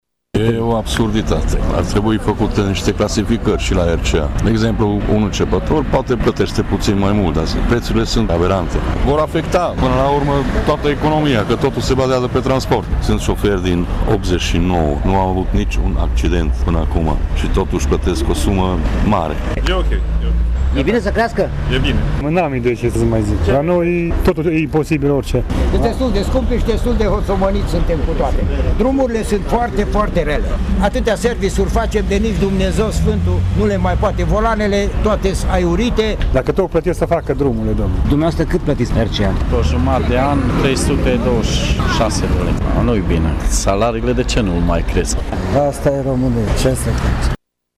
Scumpirile vor afecta , în lanț, toate produsele, susțin conducătorii auto: